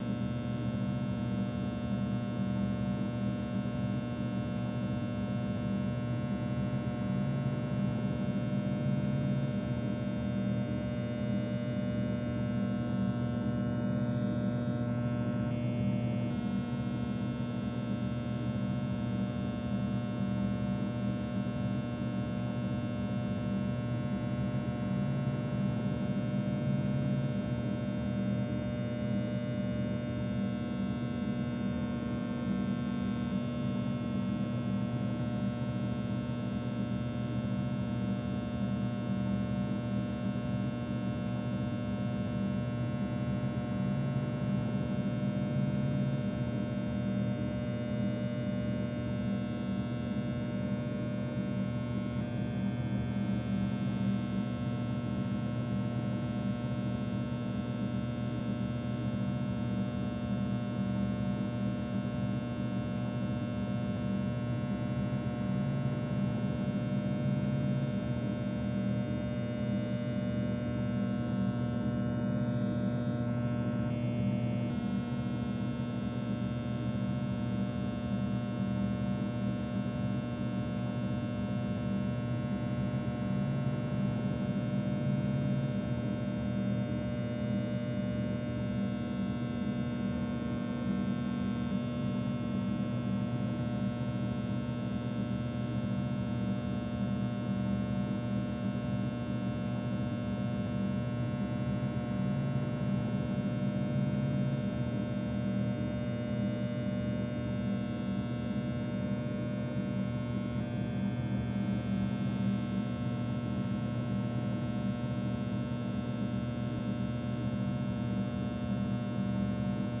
ES_Room Tone 12 - SFX Producer.wav